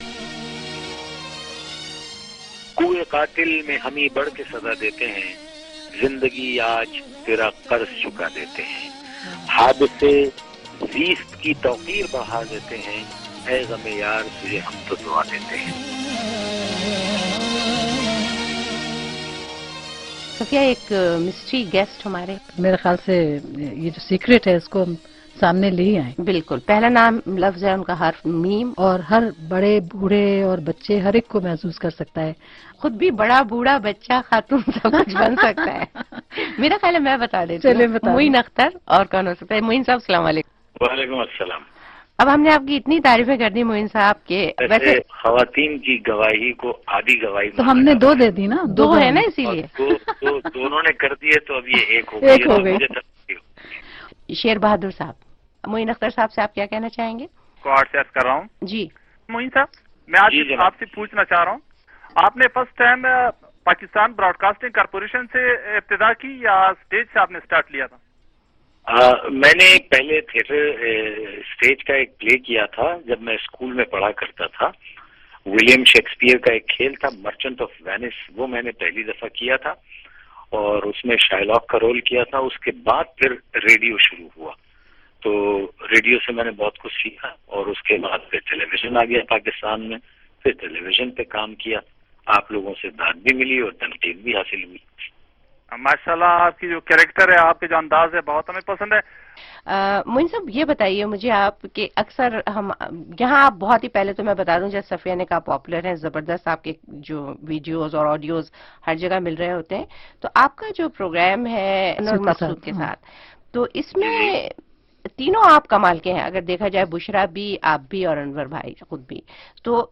زندگی آج تیرا قرض چکا دیتے ہیں: معین اختر کا ایک یادگار انٹرویو